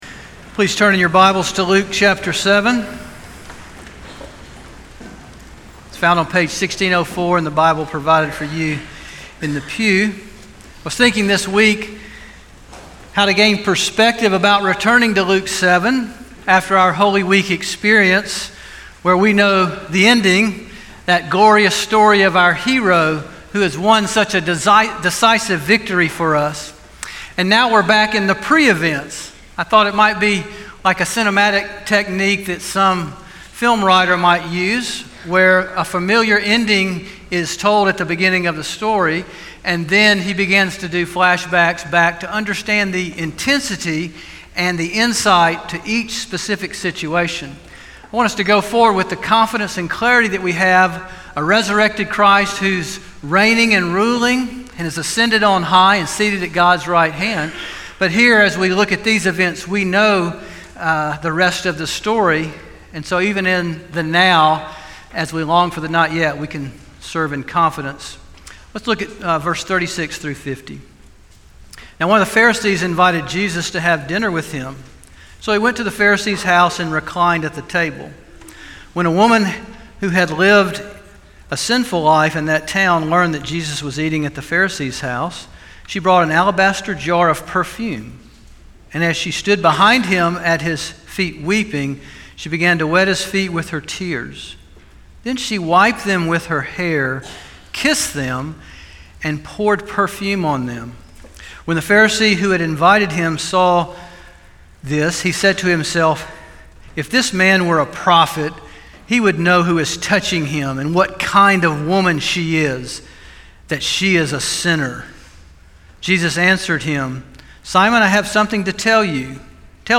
From Series: "Sunday Sermons"